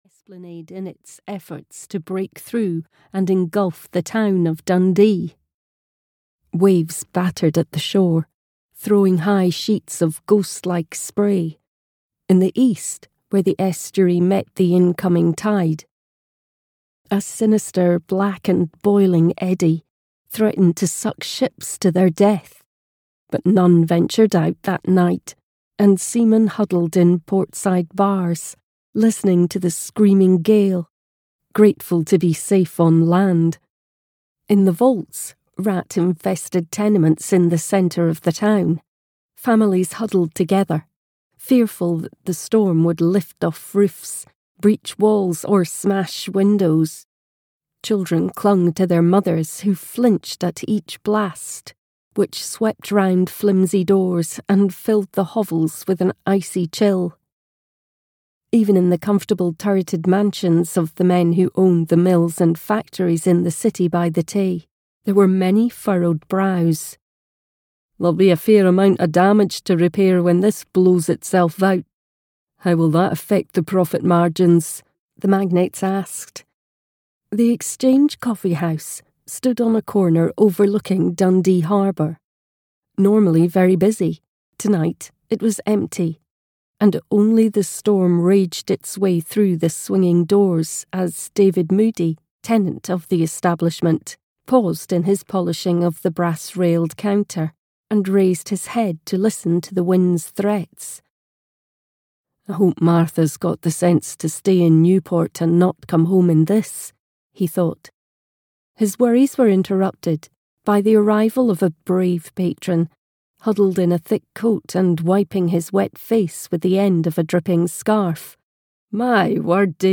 Mistress of Green Tree Mill (EN) audiokniha
Ukázka z knihy